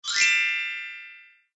SZ_MM_gliss.ogg